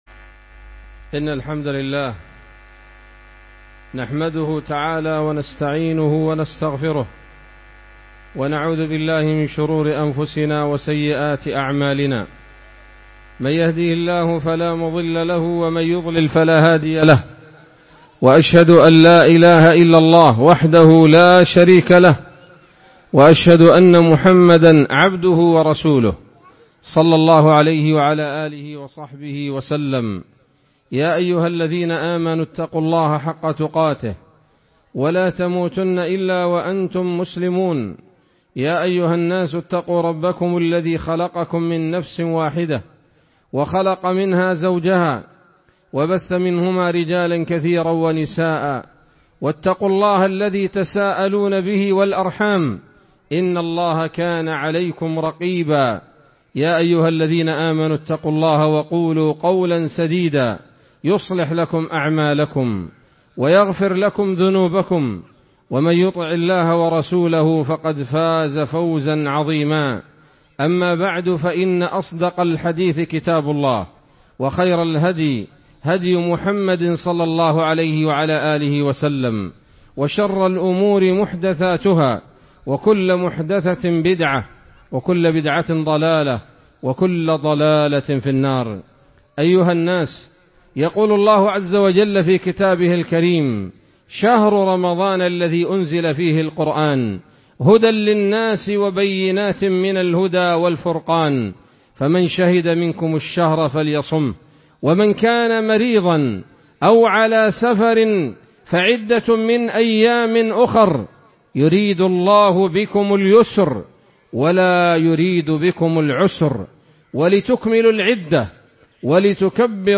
خطبة-عيد-الفطر-المبارك-1446.mp3